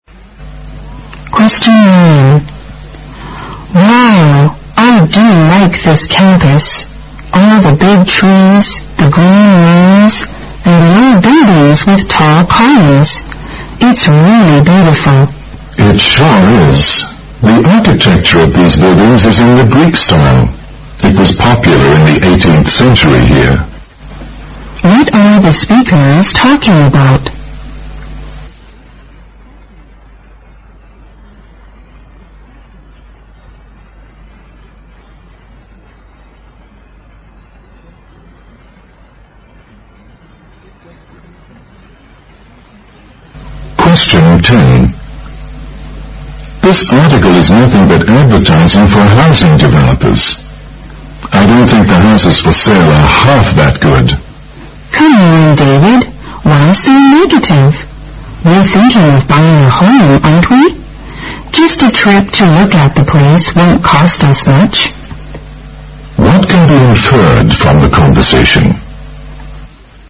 在线英语听力室078的听力文件下载,英语四级听力-短对话-在线英语听力室